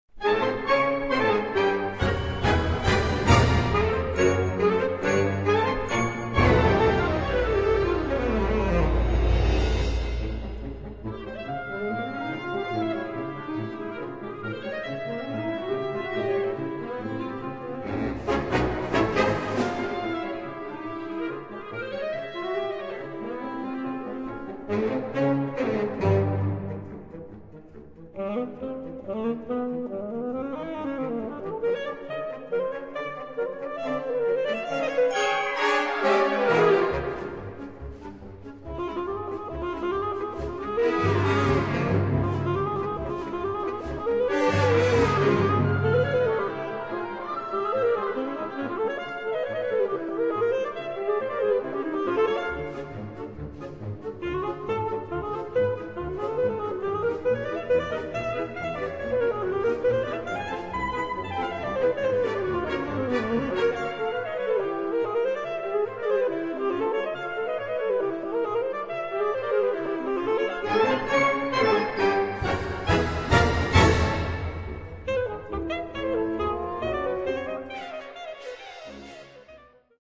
Gattung: Altsaxophon & Klavier (sehr schwer)